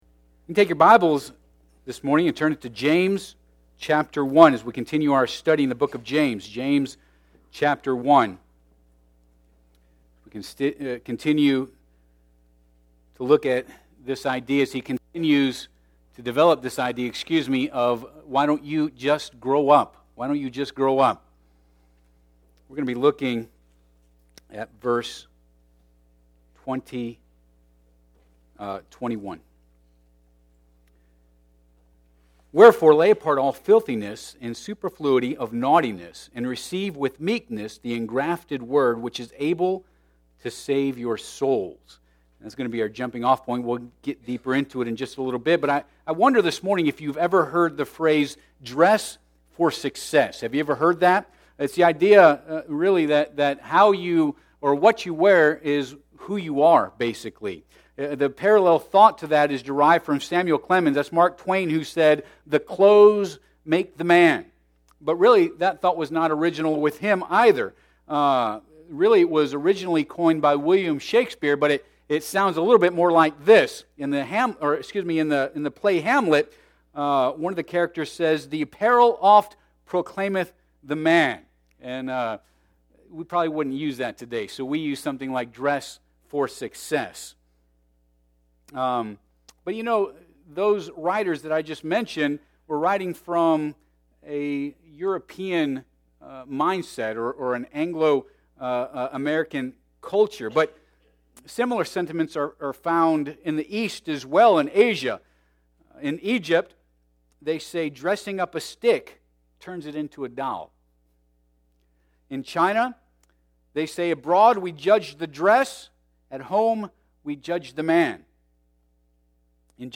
James 1:21 Service Type: Sunday AM Bible Text